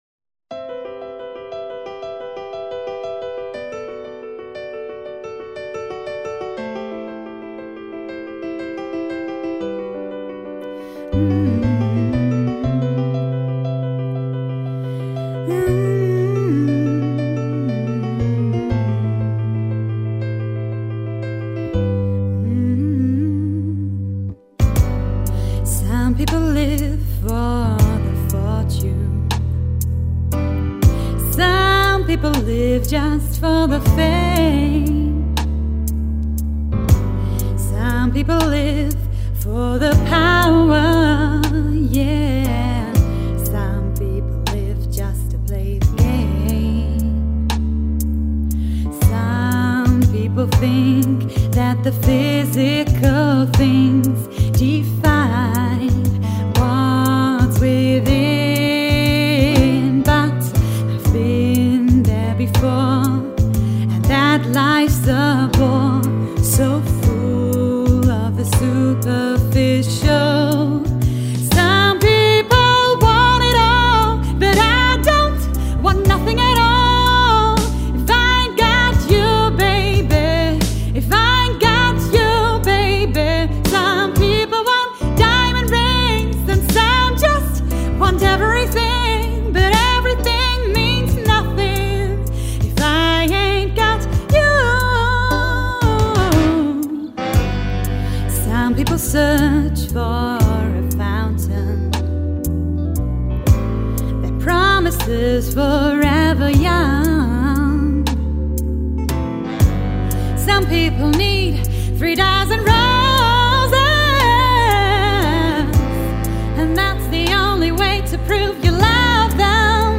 Eine Studio-Aufnahme